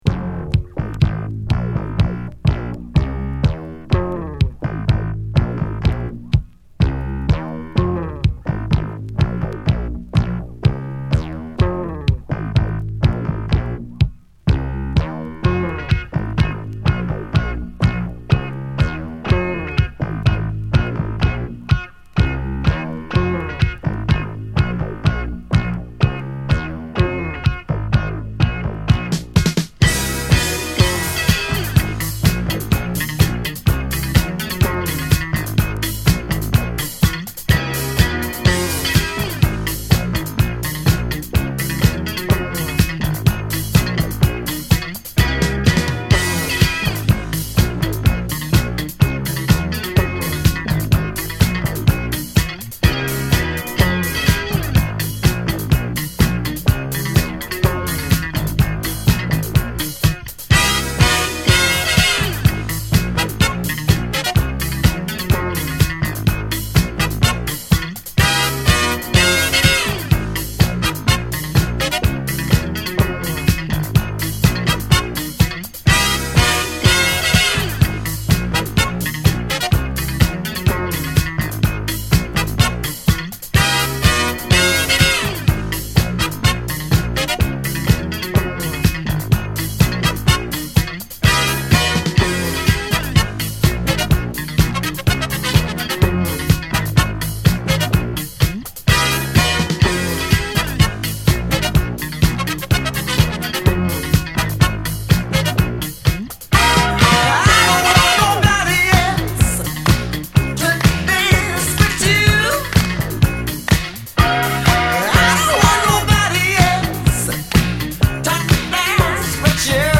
ホーンやカッティングギターが軽快に絡むディスコチューン！中盤のトリッピーなパーカッションブレイクは圧巻です！